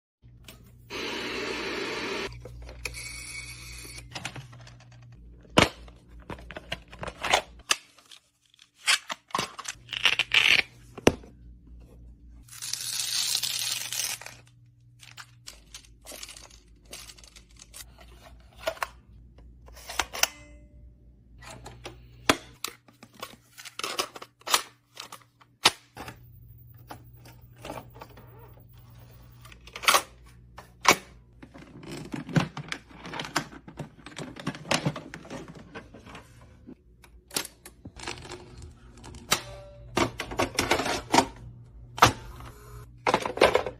SATISFYING SOUNDS OF THE 80s sound effects free download